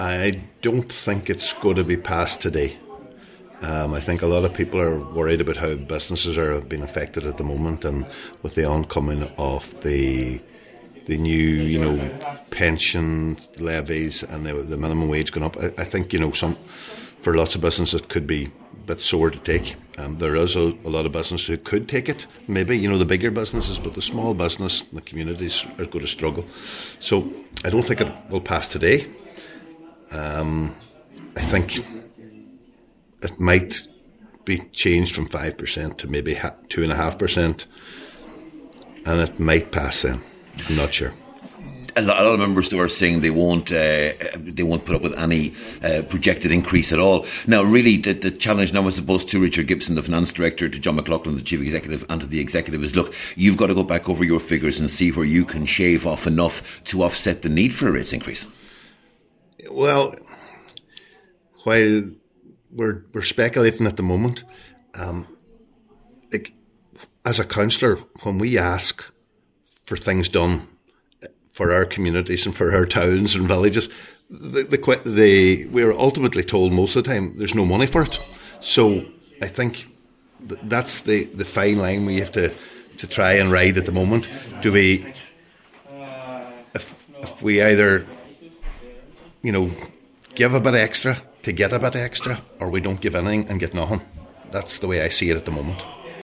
Cllr Ali Farren, of the 100% Redress Party, says it is now looking increasingly likely that the meeting will be adjourned until next week: